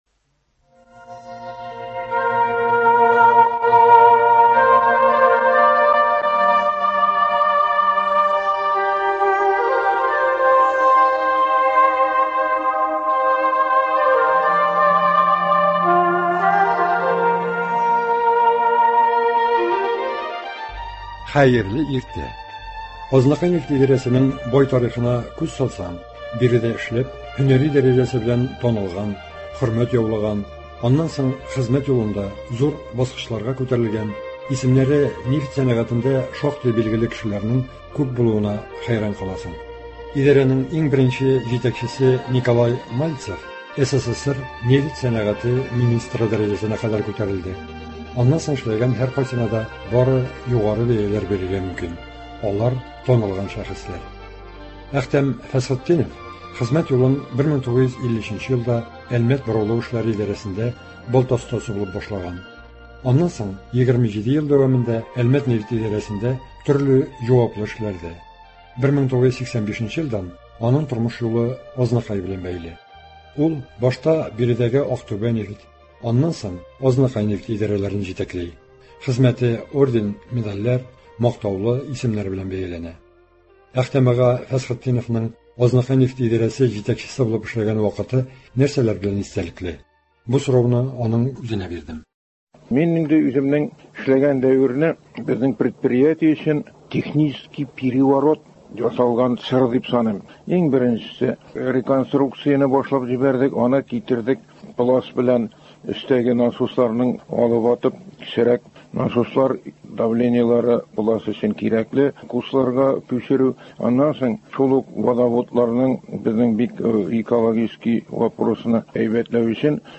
әңгәмә..